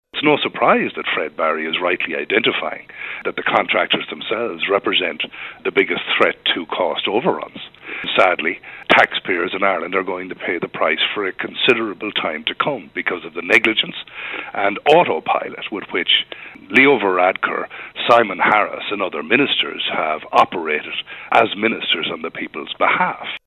Sligo, Leitrim and South Donegal Deputy Marc MacSharry, of the Public Accounts Committee, says the final price should have been agreed long in advance: